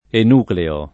enucleo [ en 2 kleo ]